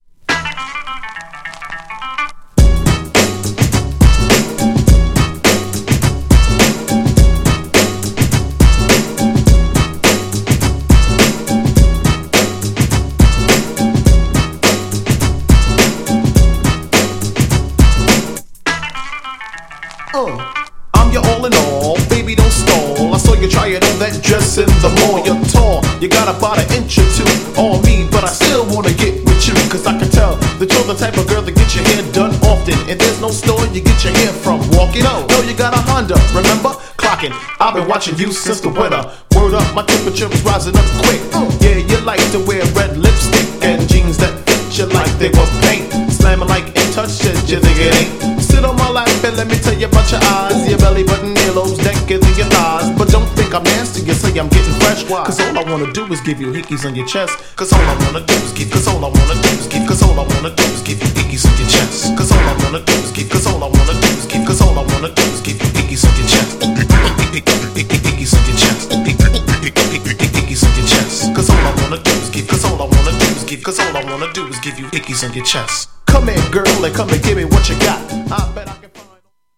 GENRE Hip Hop
BPM 101〜105BPM